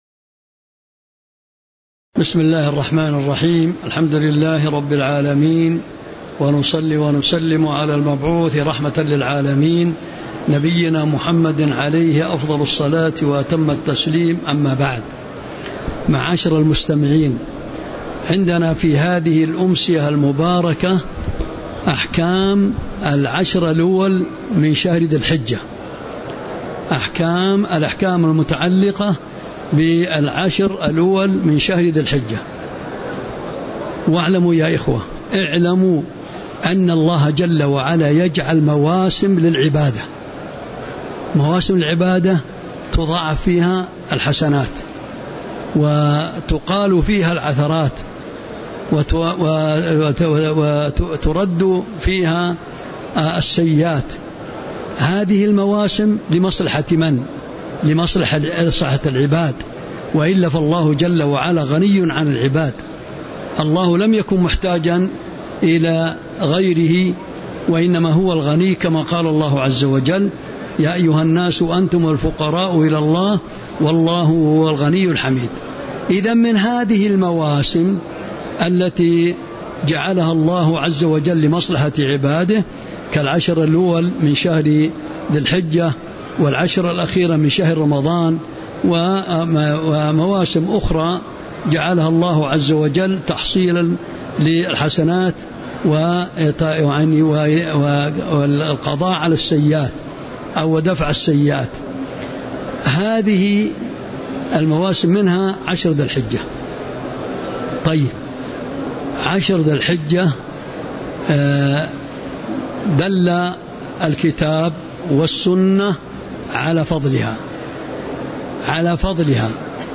تاريخ النشر ٢٨ ذو الحجة ١٤٤٤ هـ المكان: المسجد النبوي الشيخ